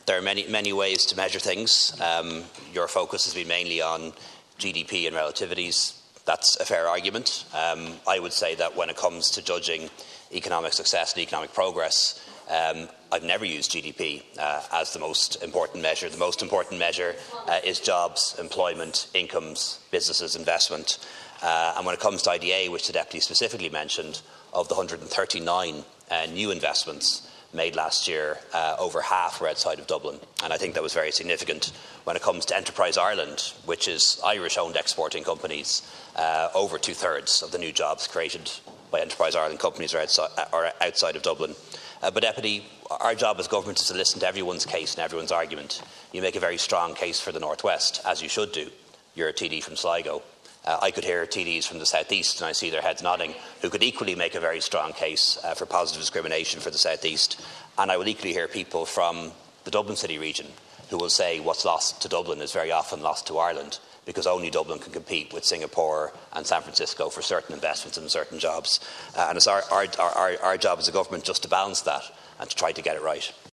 Well, the Taoiseach says Government is making efforts to ensure there is an equal share of investment across the country: